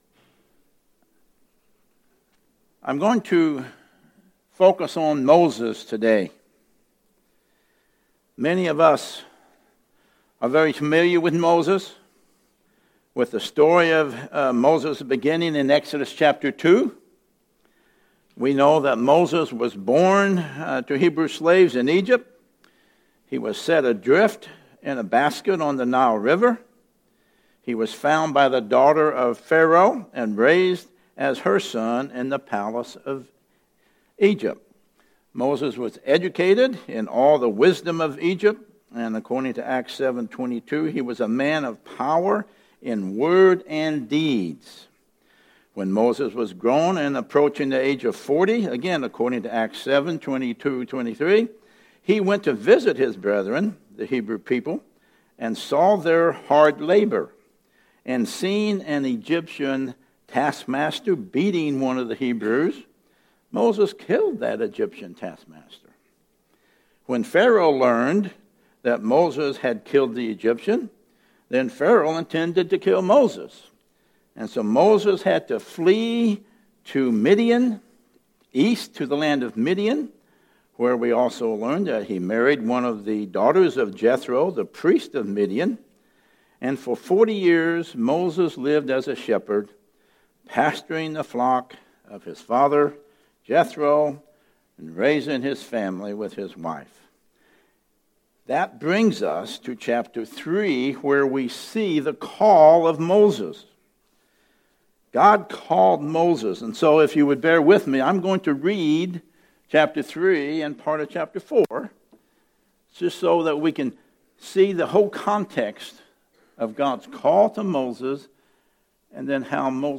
Passage: Exodus 3:1-4:20 Service Type: Sunday Message Topics